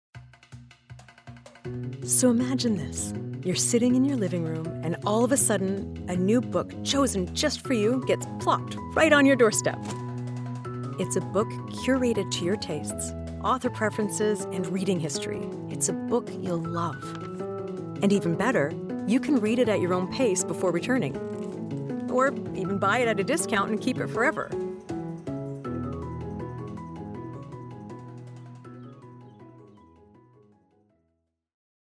Online Ad Sample (EN)
Clients describe her sound as engaging, distinct, and easy on the ears - like a trusted guide who knows how to have fun.
Broadcast-quality home studio | Fast, reliable turnaround | Friendly, professional, and directable